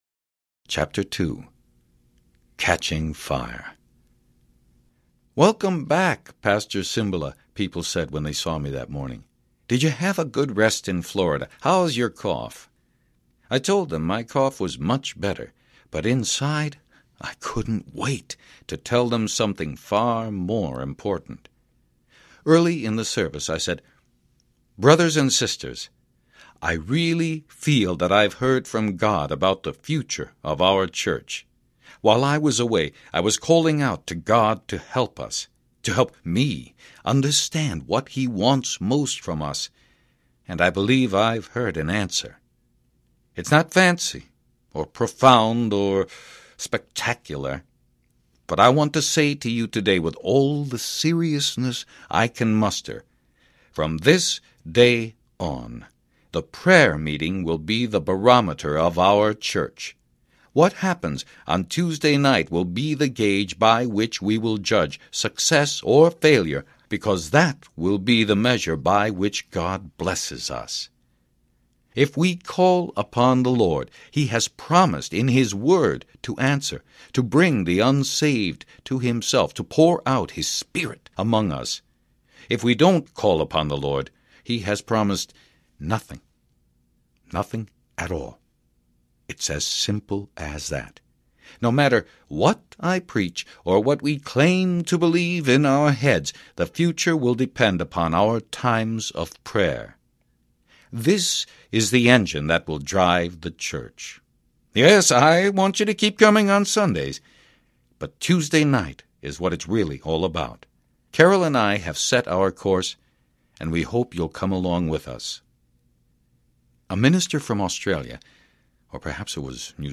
Fresh Wind, Fresh Fire Audiobook
Narrator
5.3 Hrs. – Unabridged